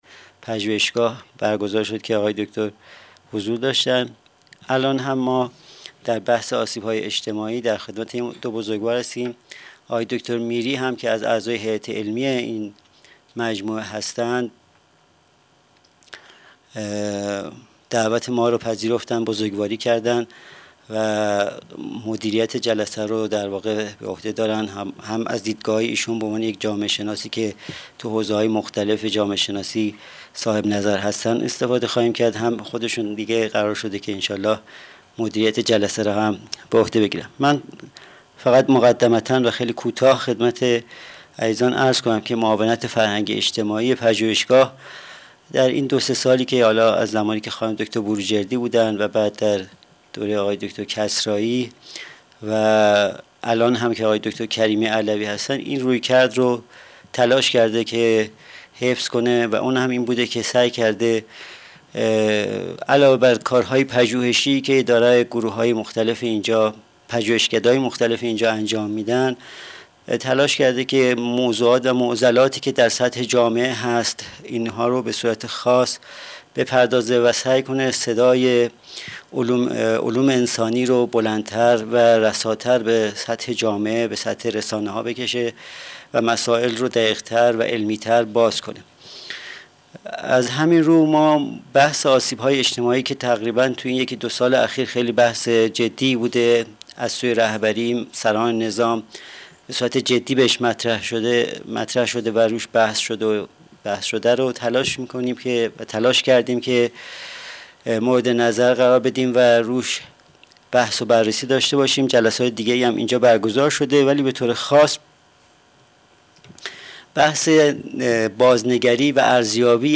سالن حکمت